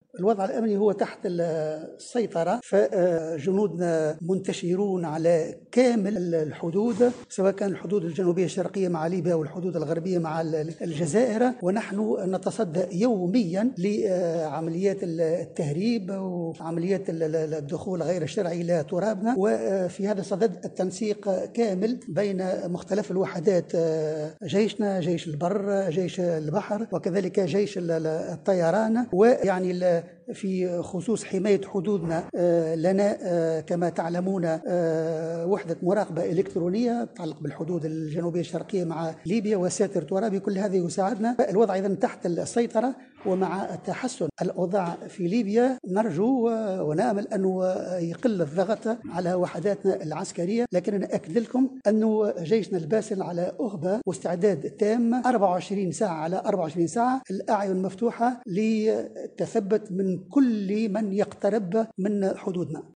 أكد وزير الدفاع ابراهيم البرتاجي في تصريح لمراسلة الجوهرة "اف ام" أن الوضع الأمني على الحدود الجزائرية و الليبية تحت السيطرة مؤكدا أن وحدات الجيش الوطني منتشرة بشكل كامل على طول الحدود مع البلدين.